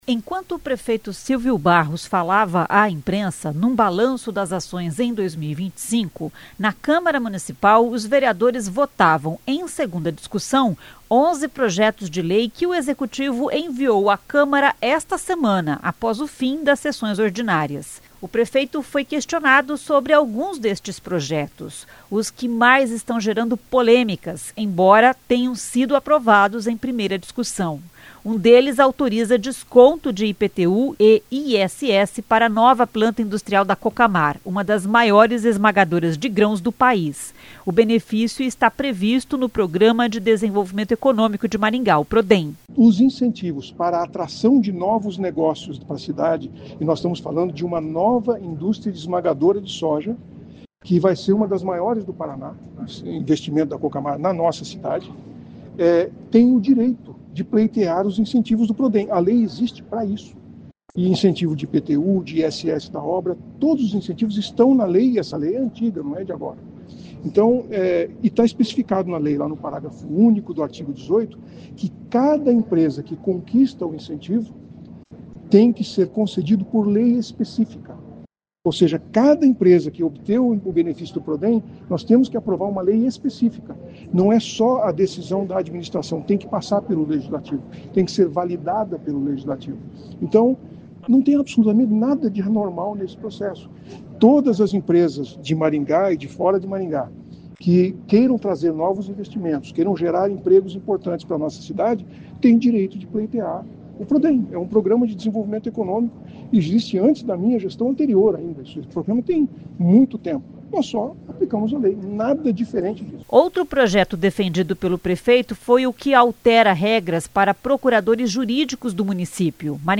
Em coletiva de imprensa, o prefeito Silvio Barros respondeu questionamentos sobre estes projetos.